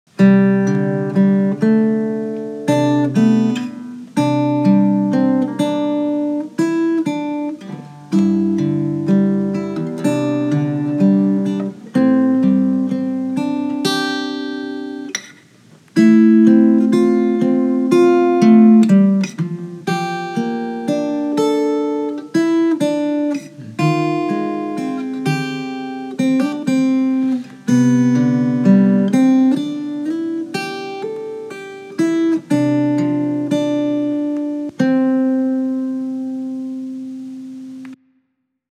音乐疗法——第一期第三首 安慰，甚至是治愈，用简单而温暖的吉他音乐，让人们感受到亲切和温馨，找到共鸣和安慰 Comfort, even healing, using simple and warm guitar music to make people feel kind and warm, finding resonance and comfort 快来听听这首音乐，与你产生了什么样的共鸣！